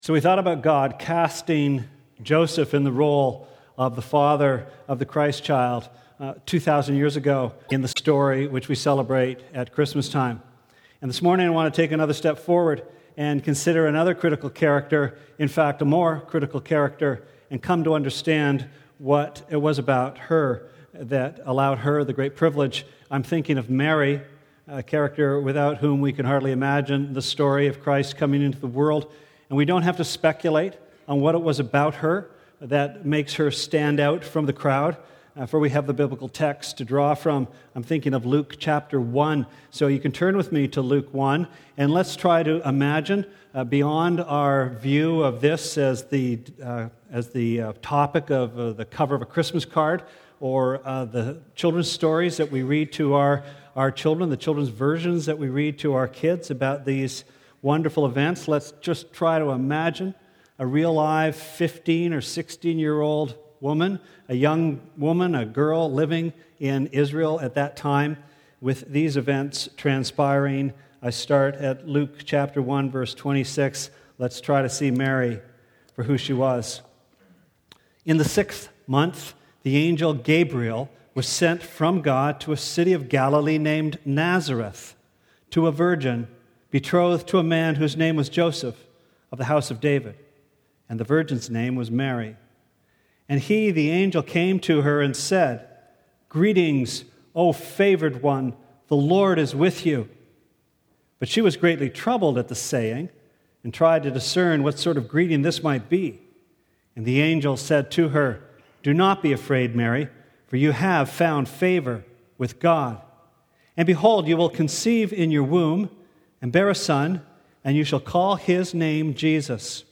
Dec 23rd Sermon